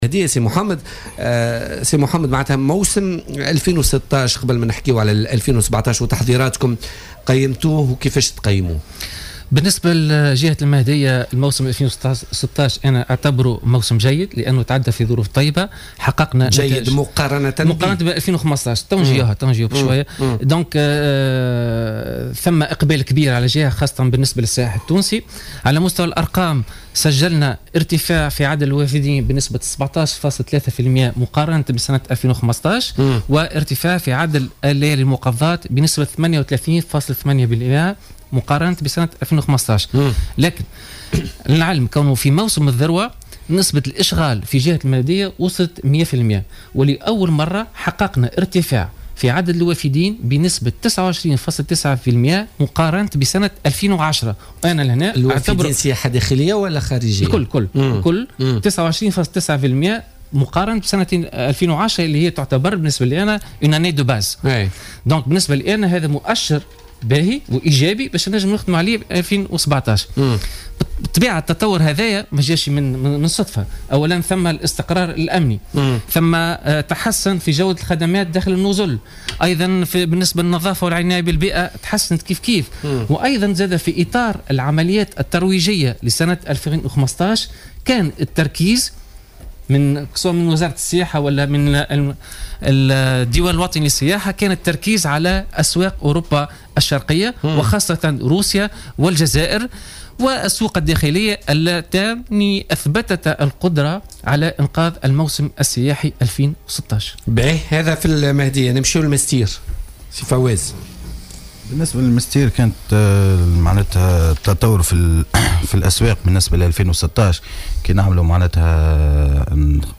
أكد اليوم الأربعاء المندوبون الجهويّون للسياحة في كل من سوسة والمنستير والمهدية لـ "الجوهرة اف أم" في برنامج "بوليتيكا"، تسجيل نموّ مهم بالقطاع السياحي بجهة الساحل العام الماضي.